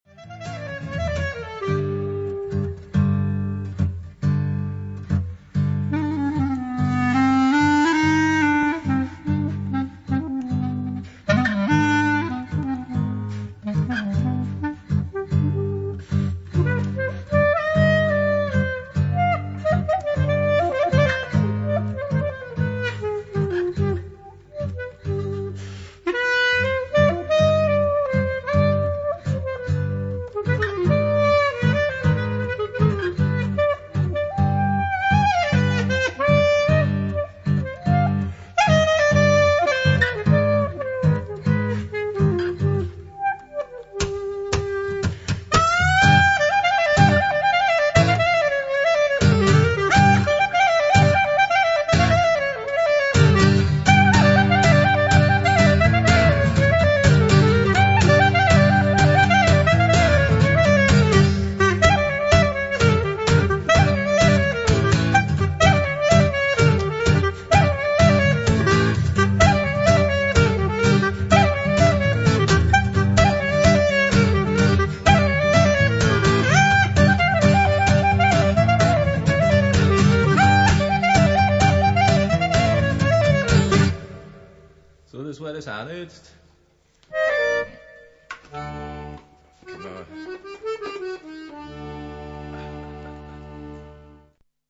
Aufgenommen in Park Studios, Linz/Austria